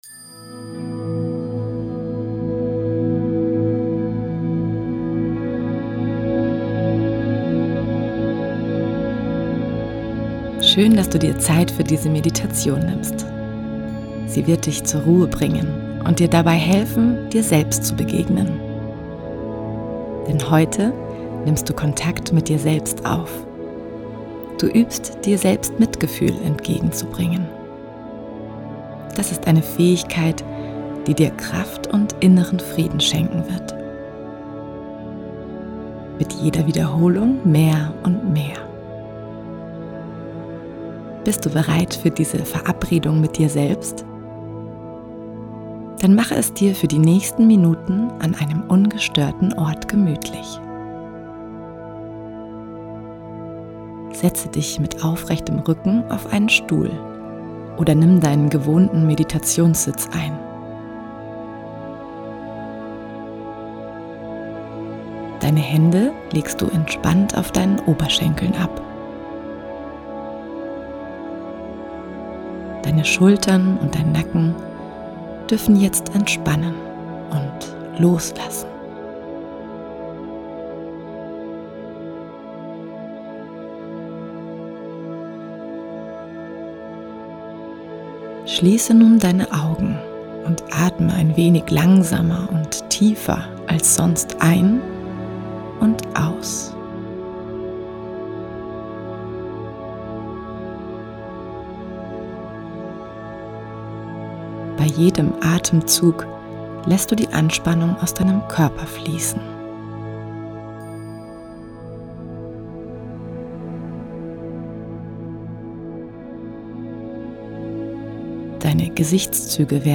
Deine tägliche Meditation
Meditation-Selbstmitgefuehl.mp3